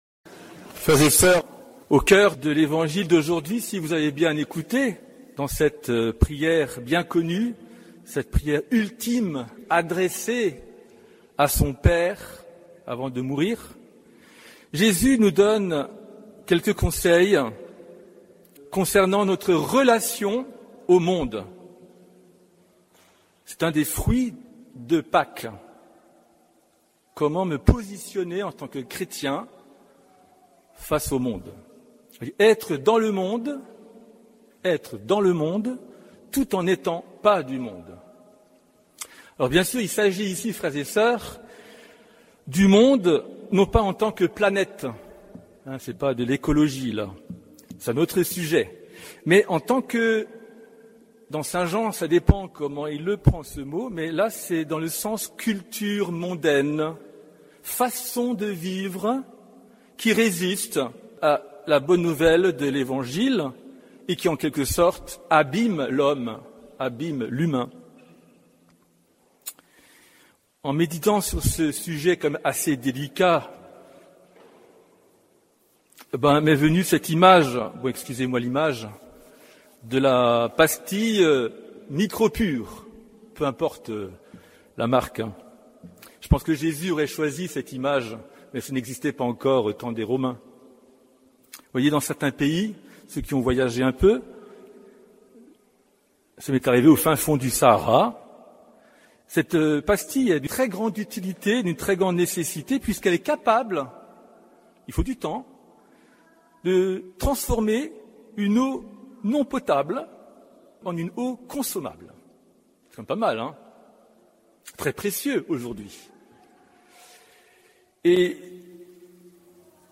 Homélie du septième dimanche de Pâques